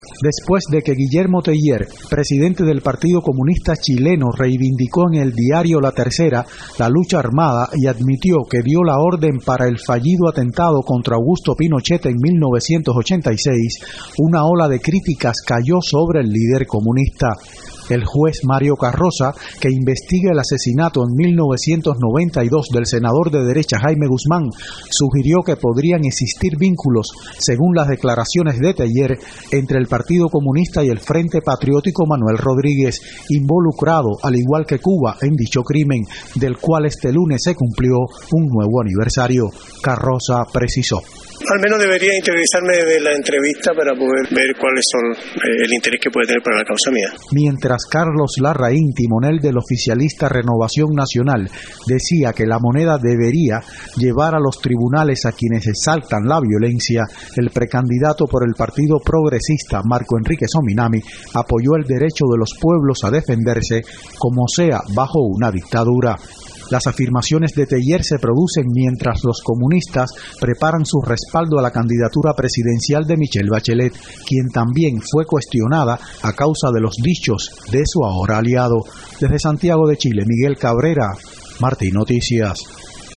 Ola de críticas contra líder de los comunistas chilenos por defender la violencia contra el régimen militar de Augusto Pinochet. Desde Santiago de Chile